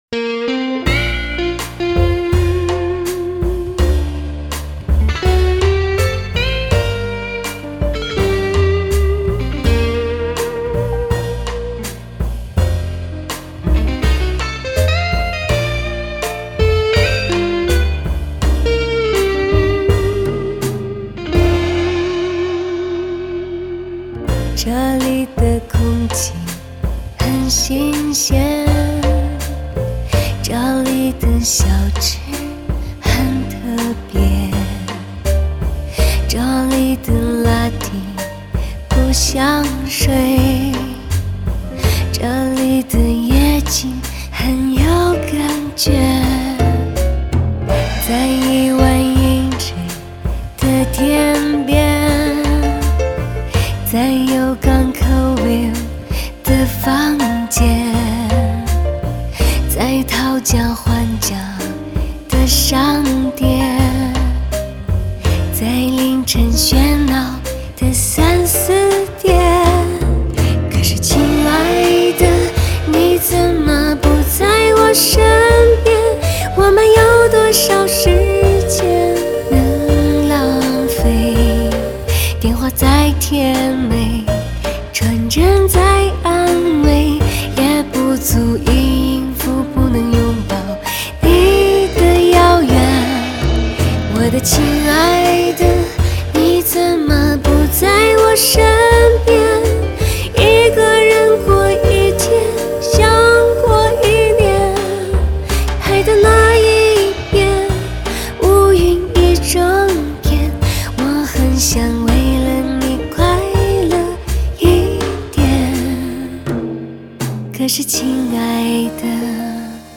风格流派：Pop